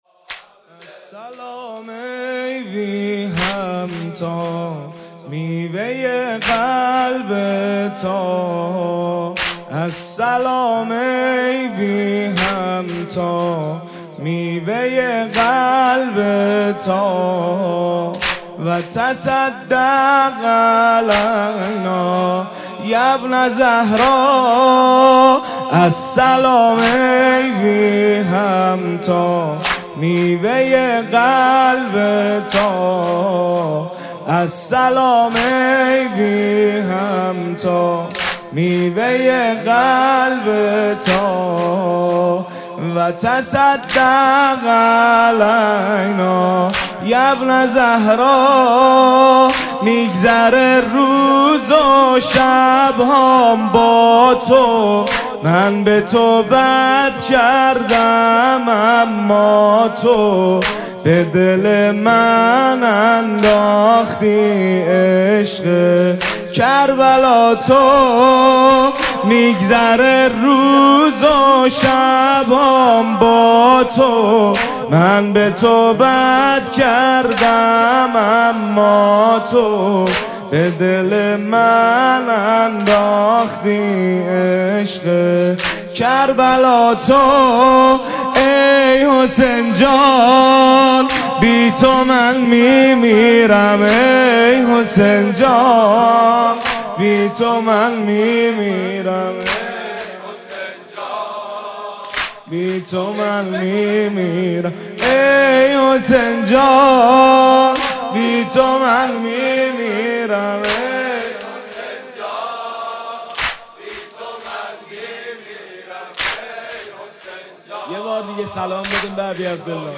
مناسبت : دهه اول صفر